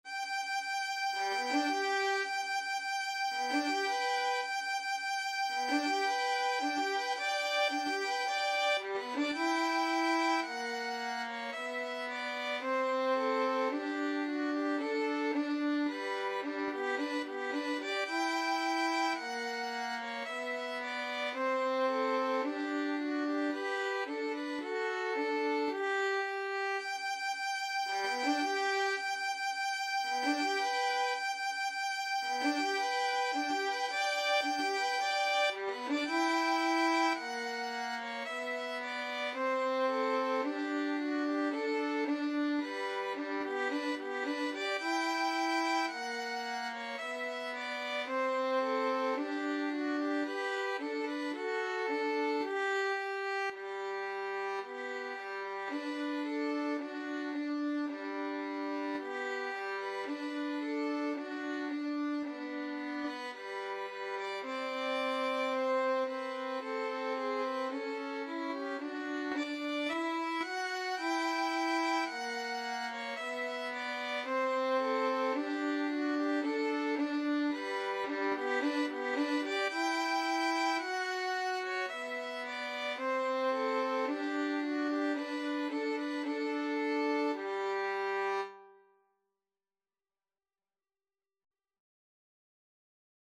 Violin 1Violin 2
4/4 (View more 4/4 Music)
Andante maestoso =c.110 =110
Violin Duet  (View more Easy Violin Duet Music)
Classical (View more Classical Violin Duet Music)